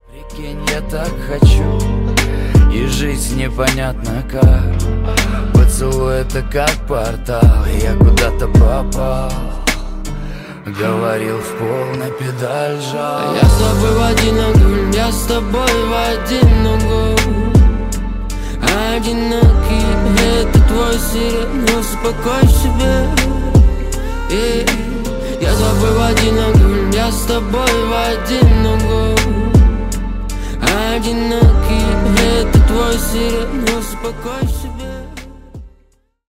Поп Музыка
грустные # спокойные